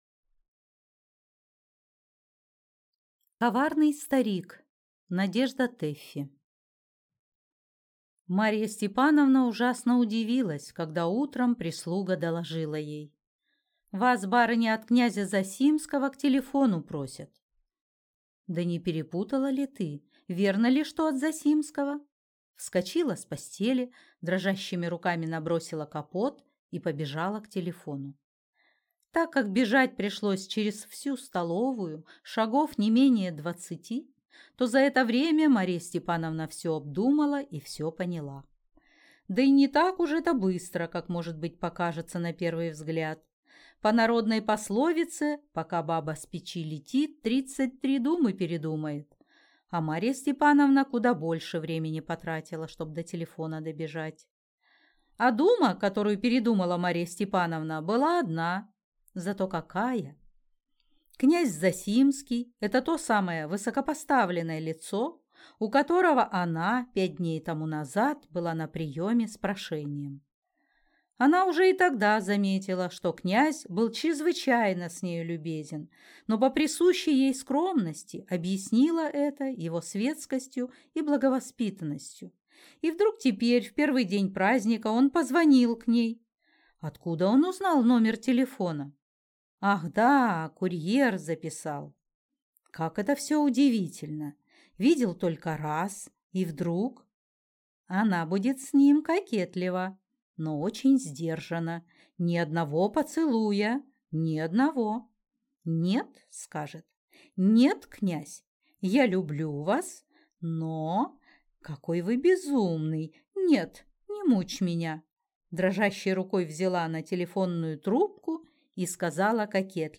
Аудиокнига Коварный старик | Библиотека аудиокниг